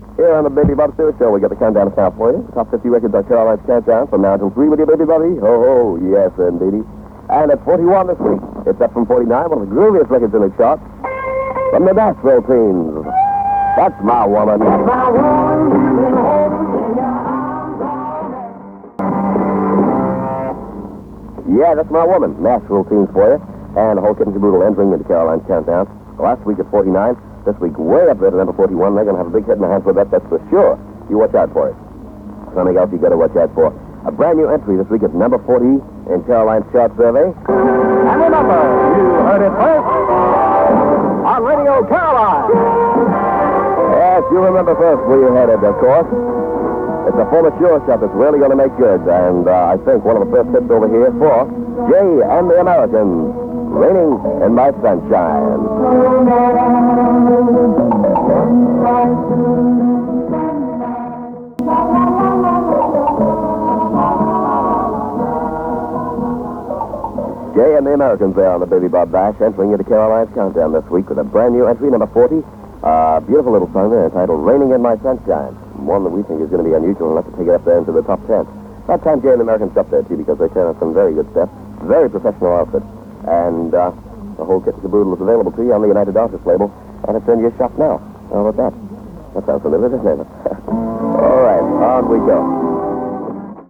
A short clip from the same chart being hosted on Radio Caroline North